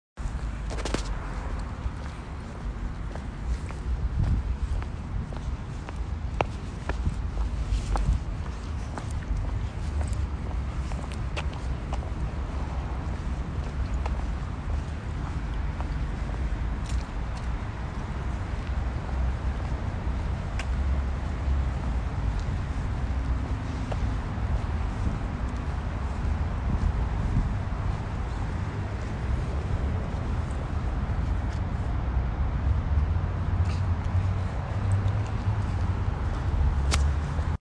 Field Recording #2
Sounds: Clothing rubbing together, distant and closer cars moving, engines sputtering, footsteps, light key jingling.
2/23/2024, 10:17 PM ET, Hempstead Turnpike/California Avenue
The sound furthest away from me is the rumble of cars much further down the road, just loud enough to be noticeable, getting louder as they come towards the red light.